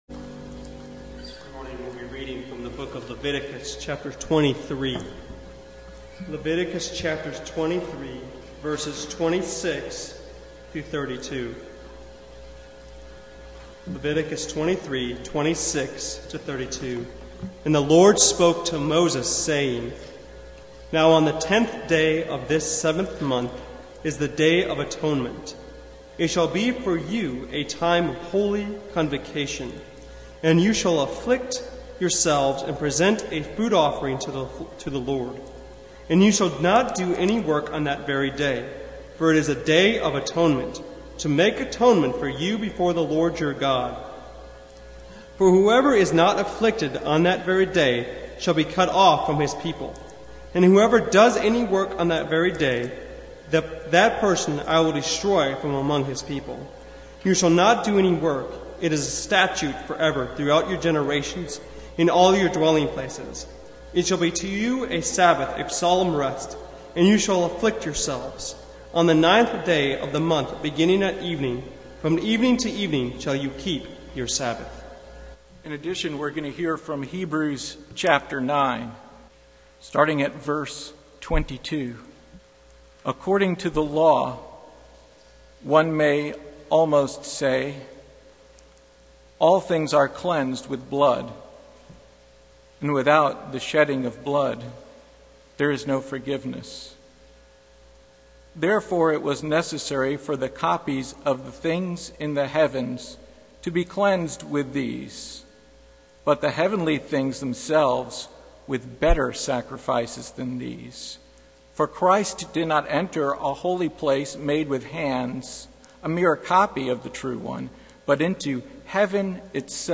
Passage: Leviticus 23:26-32 Service Type: Sunday Morning